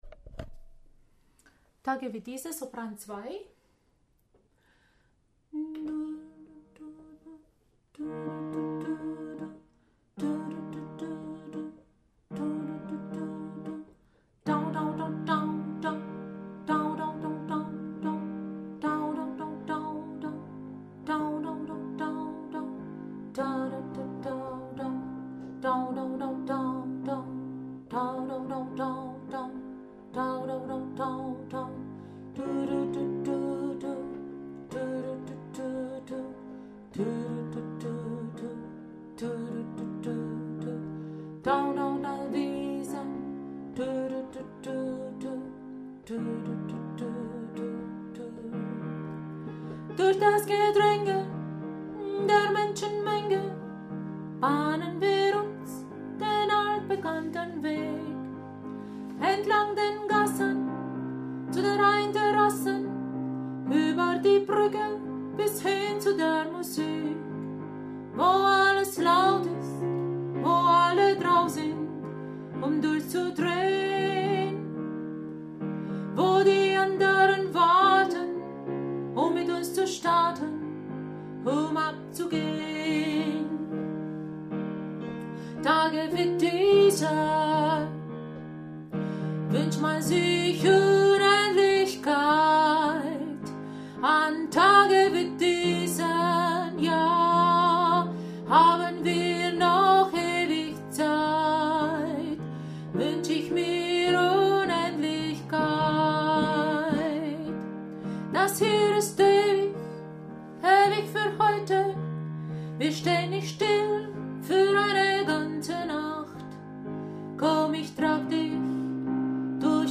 Tage wie diese – Sopran2